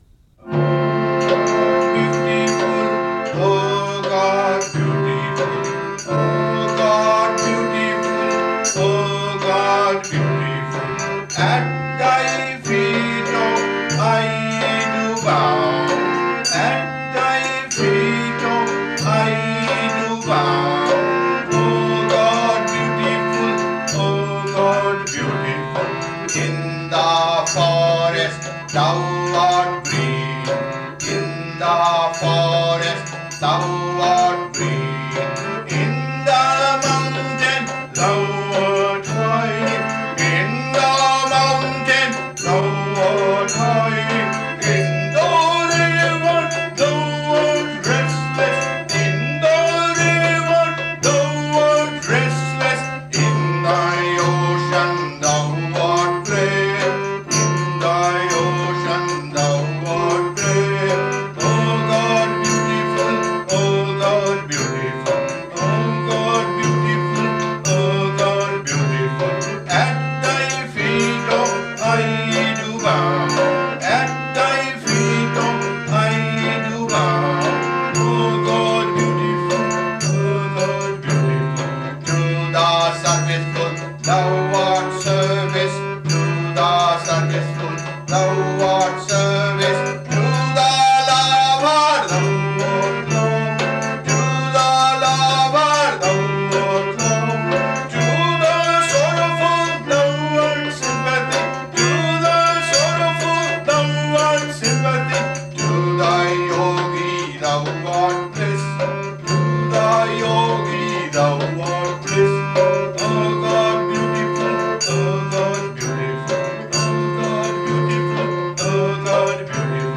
SING ALONG: This is Yogananda himself singing this Cosmic Chant , in Hindi and English.